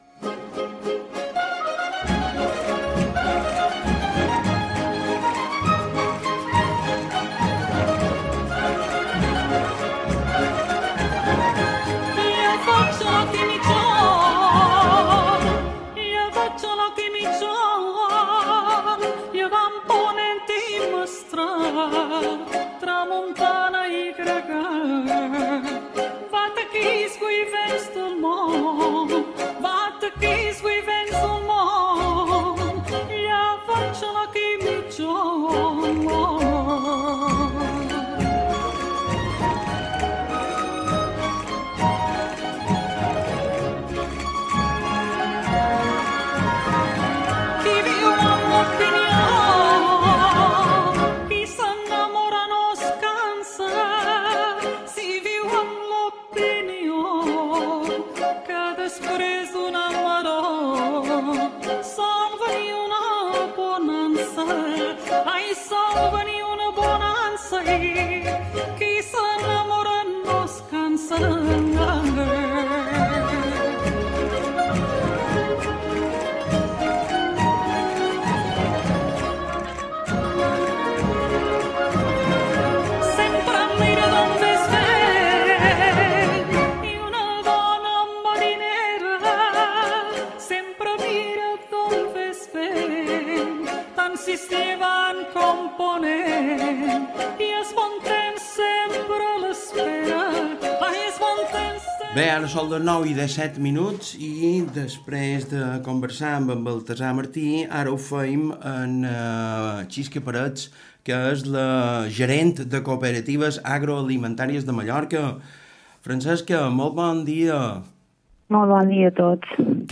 Entrevista en Ona Mediterránea – Cooperatives Agro-alimentaries de las Islas Baleares